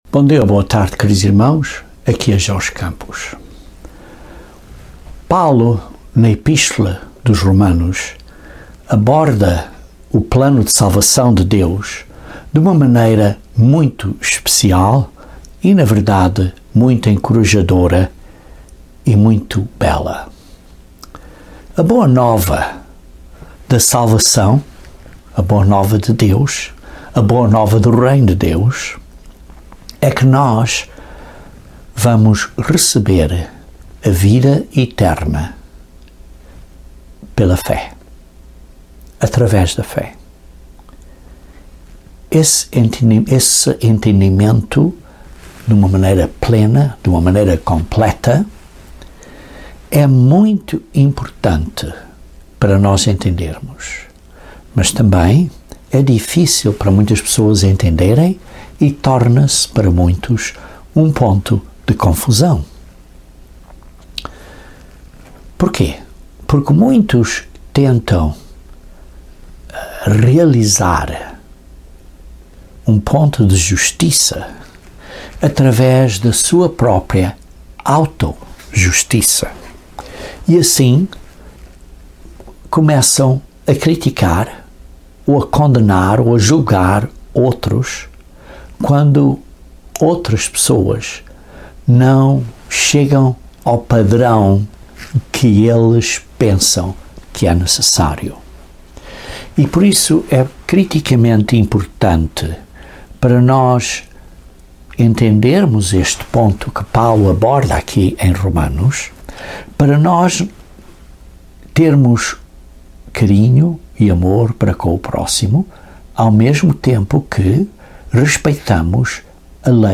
Este sermão aborda este assunto usando como base a epístola de Paulo aos Romanos.